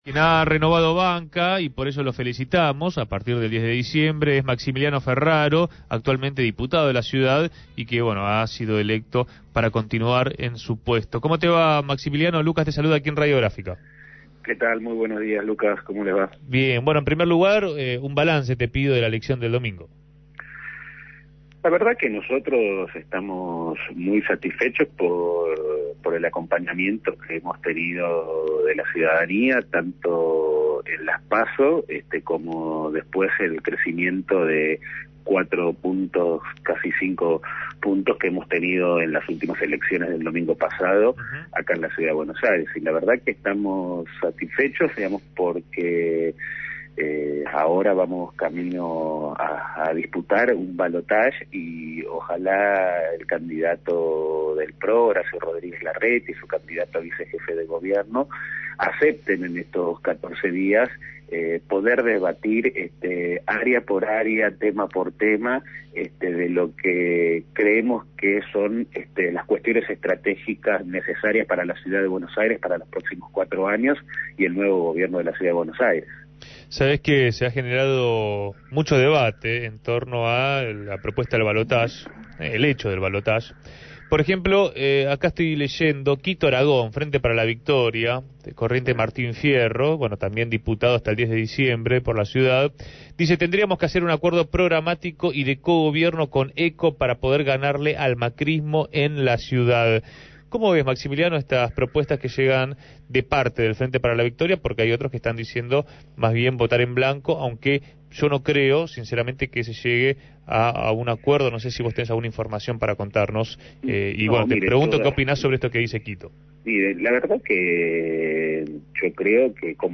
Maximiliano Ferraro, legislador porteño reelecto por ECO, dio su visión acerca de las elecciones y el próximo ballotage en Punto de Partida.